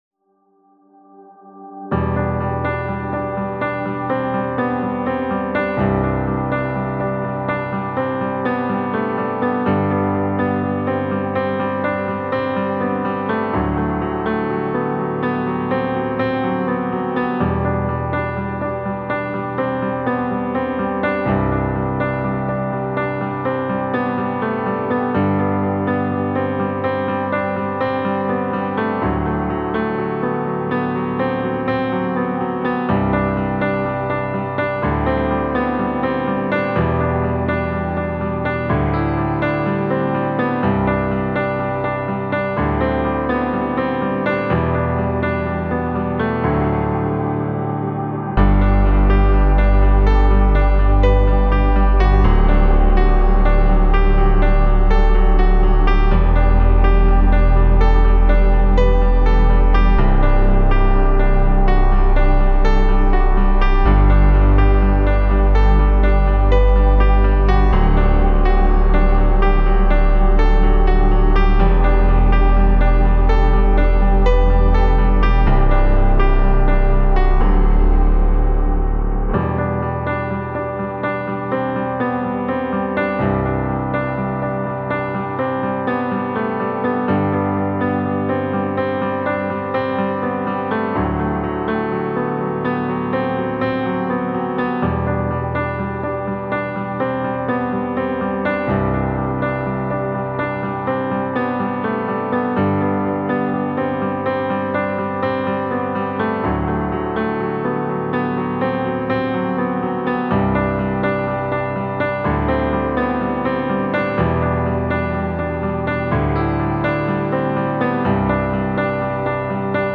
2025 in Hip-Hop Instrumentals